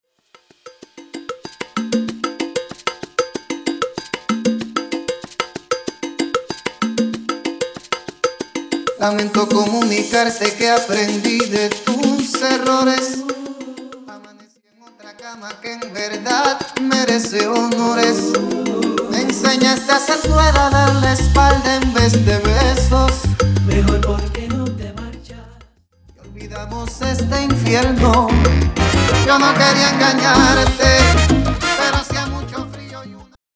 Intro Acapella Dirty